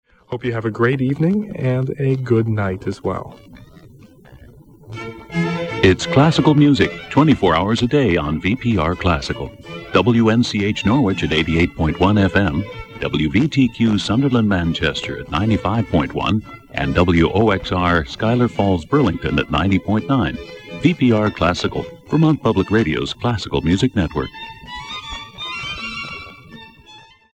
WVTQ Top of the Hour Audio: